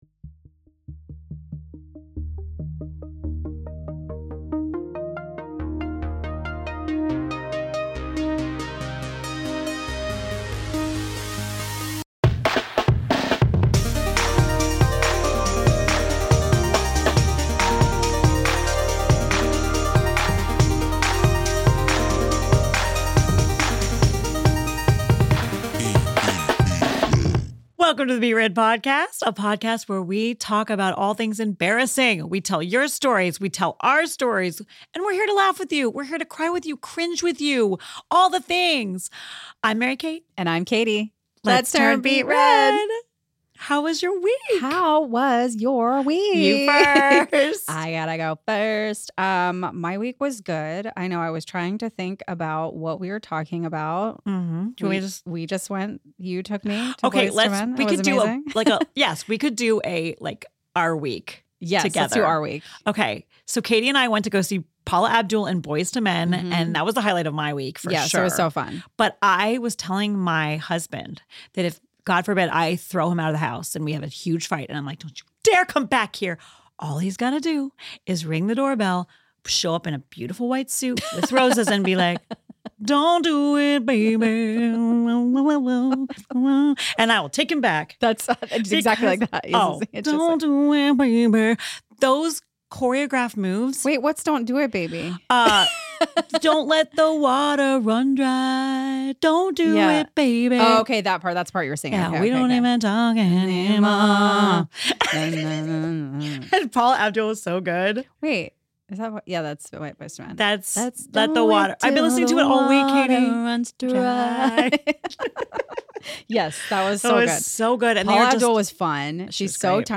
PROJKT studios in Monterey Park, CA.